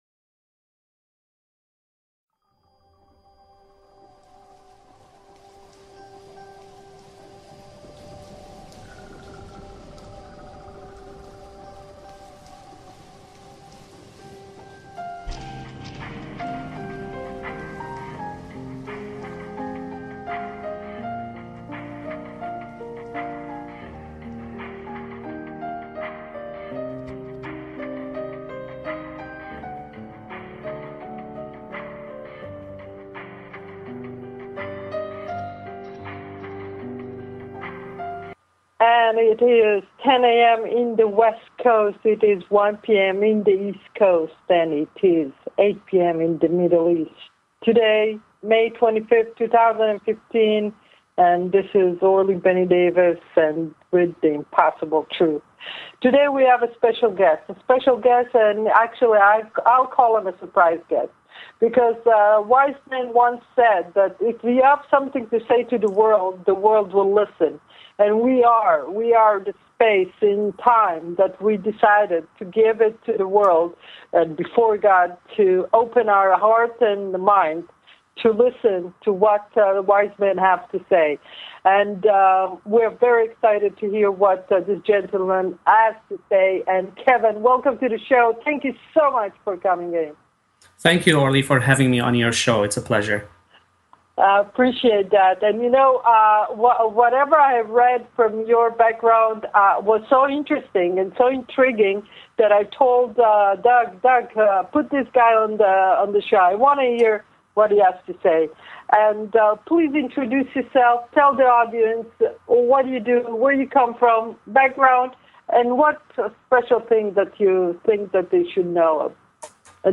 Talk Show Episode, Audio Podcast